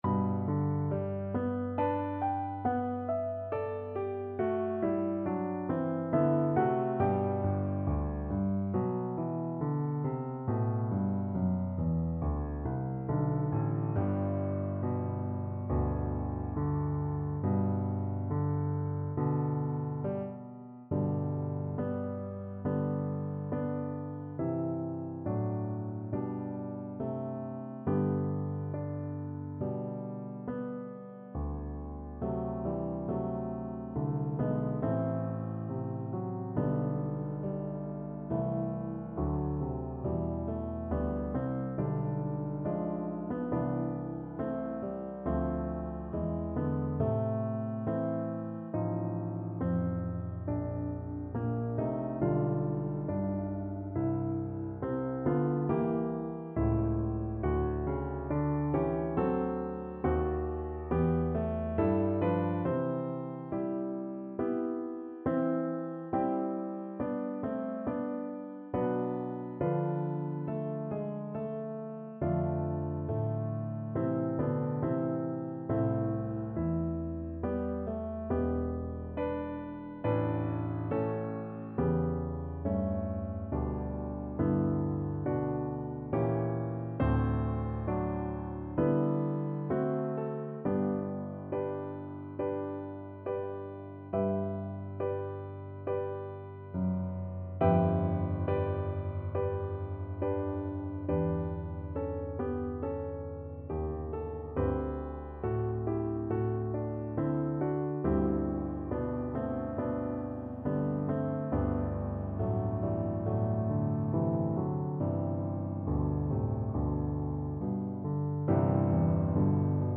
Slow =c.69
G major (Sounding Pitch) (View more G major Music for Flute )
Classical (View more Classical Flute Music)